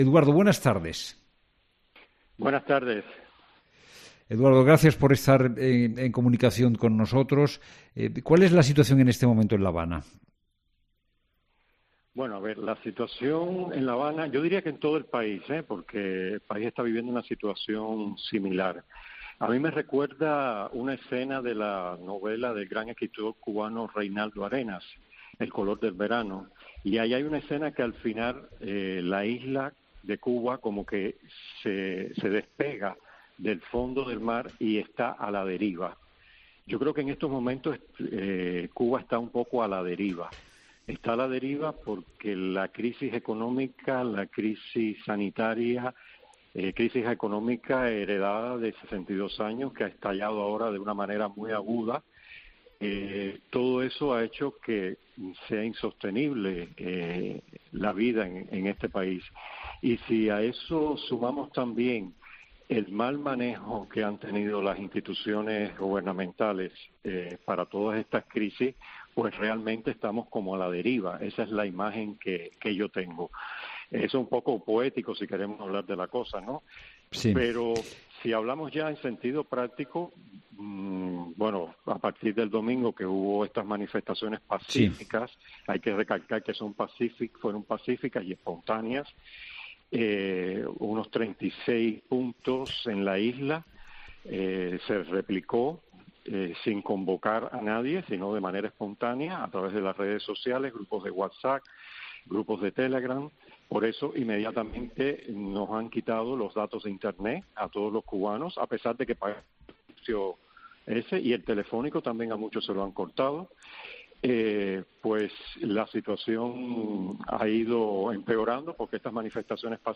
Noticias. Situación en Cuba.